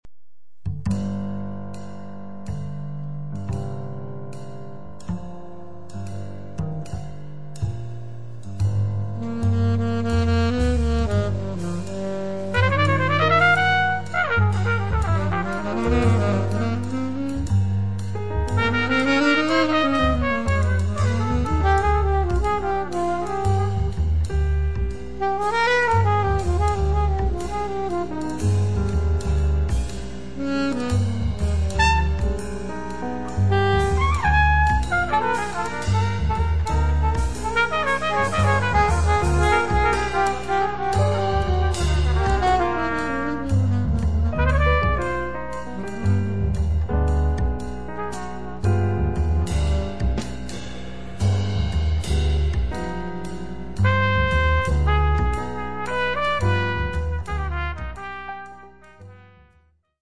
Tromba
Piano
Contrabbasso
Batteria
plastico interplay di sax-alto e tromba